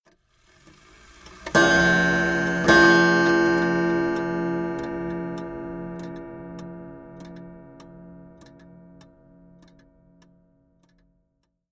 2 Chime
Tags: clock